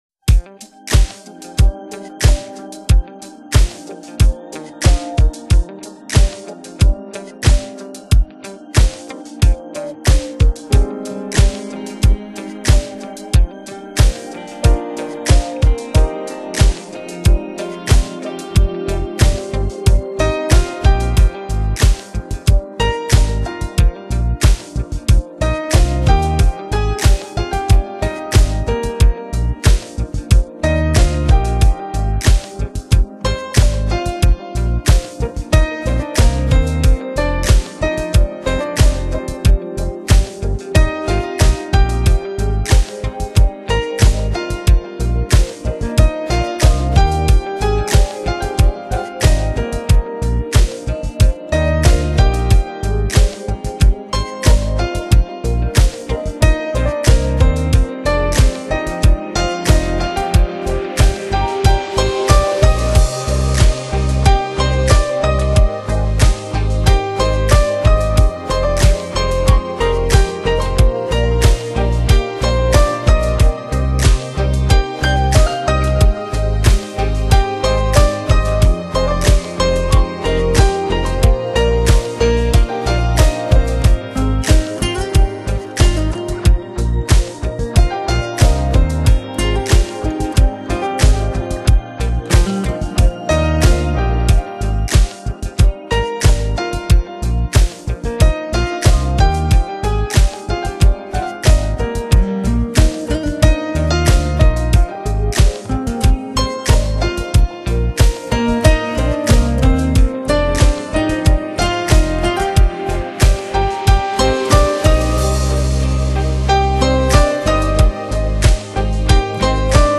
Genre: Smooth Jazz
他的音乐，演奏旋律性强，R&B与Funk味并重，非常时尚，深受都市年轻人的喜爱。